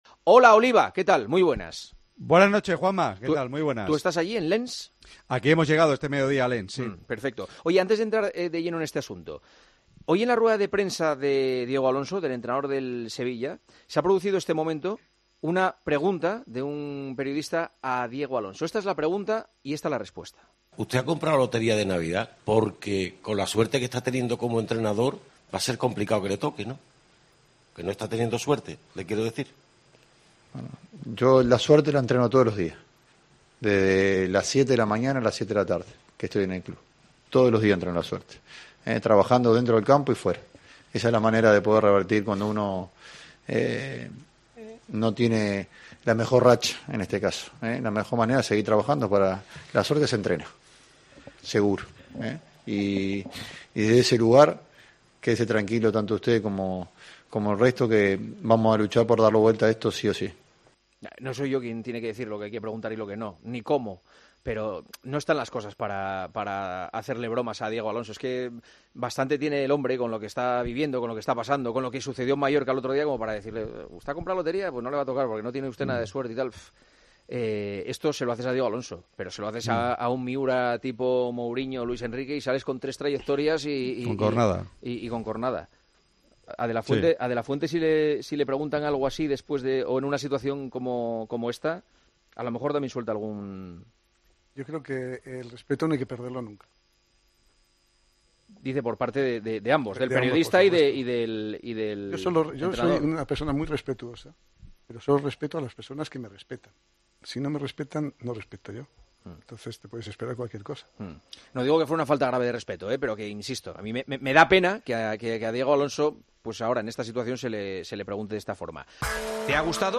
El director y presentador de El Partidazo de COPE habla sobre una de las preguntas que se le hicieron al entrenador del Sevilla en la rueda de prensa prepartido ante el Lens.